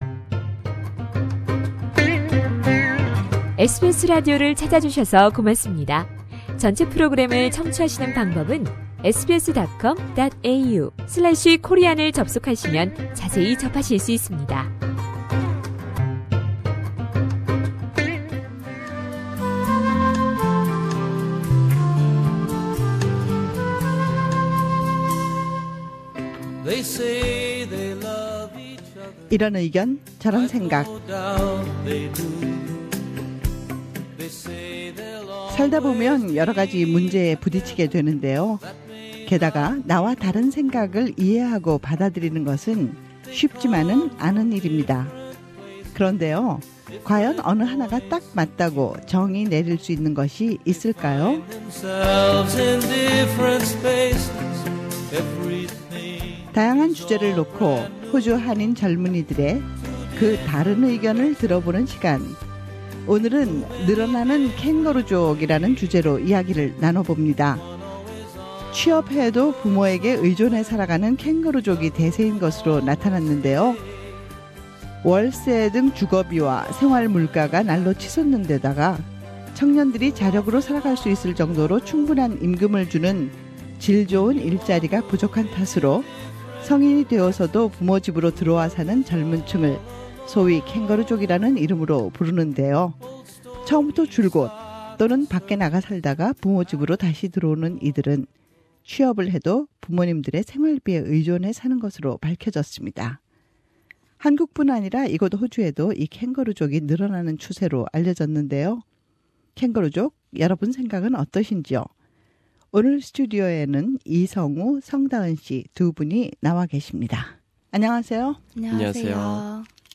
우리 주변의 다양한 이슈에 대해 호주에 사는 한인 청년들은 어떤 생각을 갖고 있을까요? '이런 생각, 저런 의견' 이번 주 토론 주제는 '캥거루 족' 입니다.